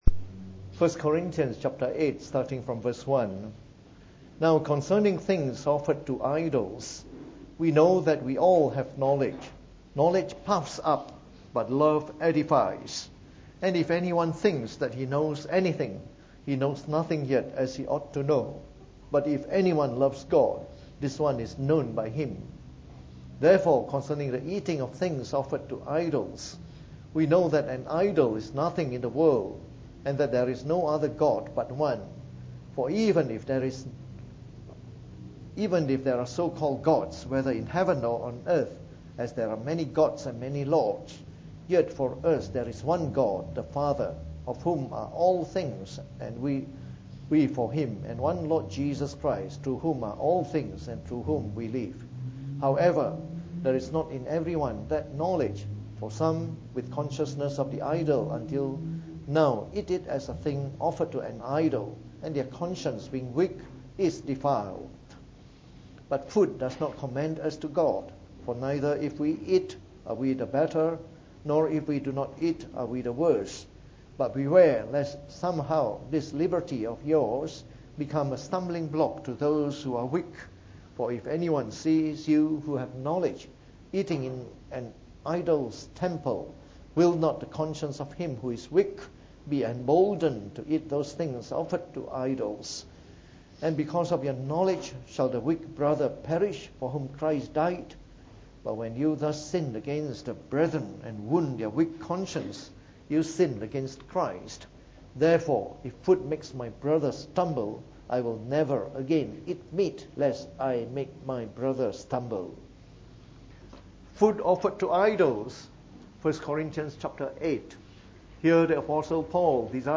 Preached on the 23rd of July 2017. From our series on 1 Corinthians delivered in the Evening Service.